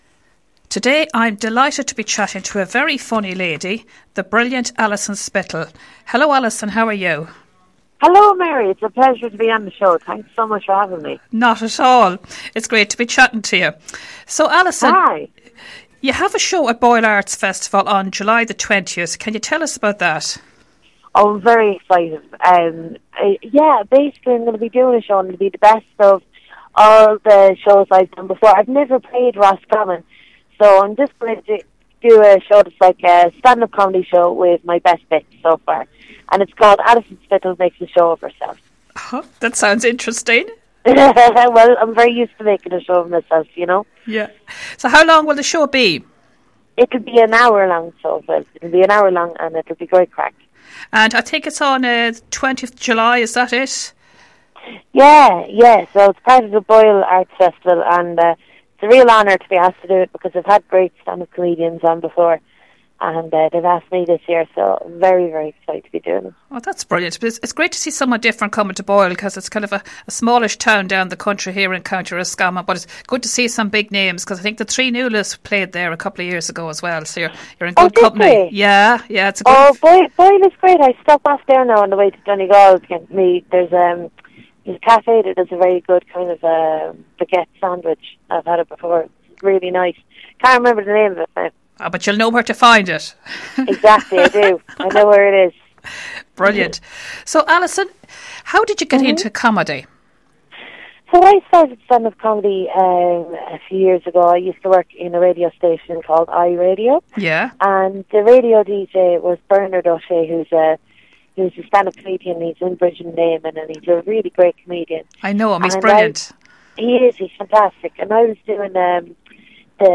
Comedian Alison Spittle Interview - RosFM 94.6